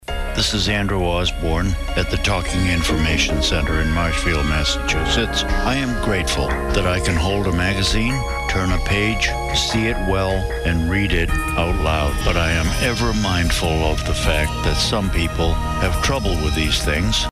There was also recorded statements from volunteers.